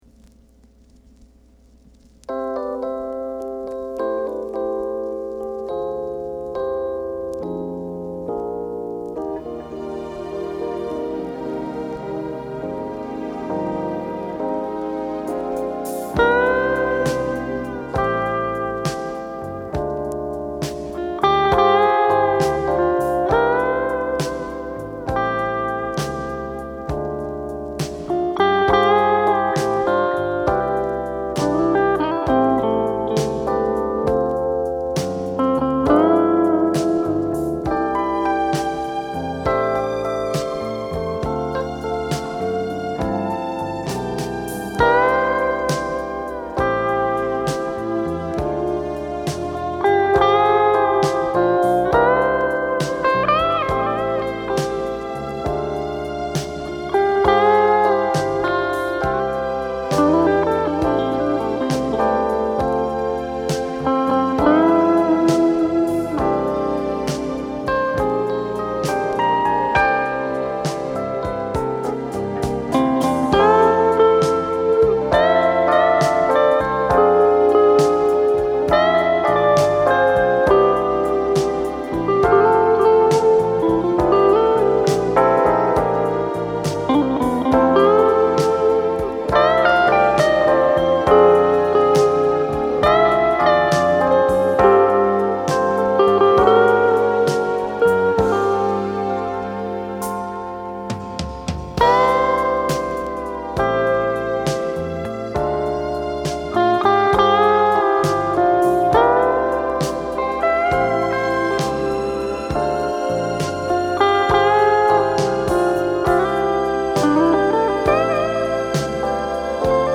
Genre: Jazz Fusion
ルーム335録音、ストリングスはキャピトル・スタジオAにて収録。
alto sax
percussion
静けさの中にギターの余韻がゆったりと広がる。